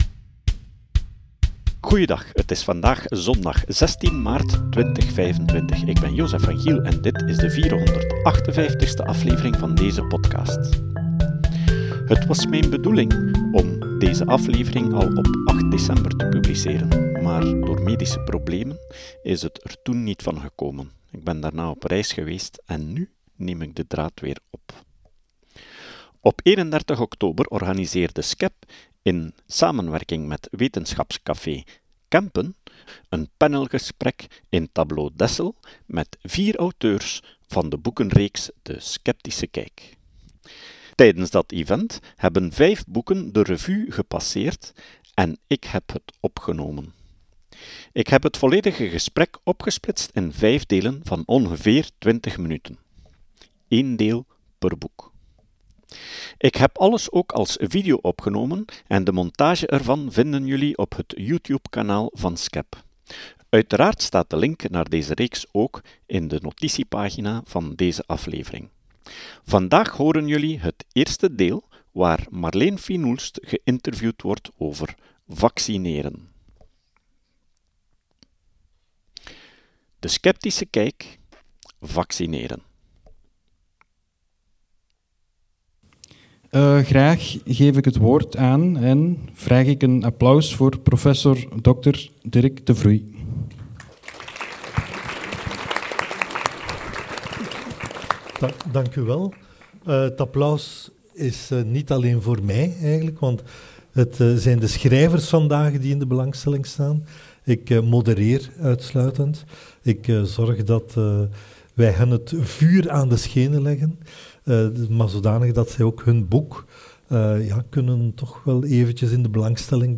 Op 31 oktober organiseerde Skepp in samenwerking met Wetenschapscafé Kempen een panelgesprek in Tabloo Dessel, met vier auteurs van de boekenreeks De Skepptische Kijk. Tijdens dat event hebben vijf boeken de revue gepasseerd en ik heb het opgenomen.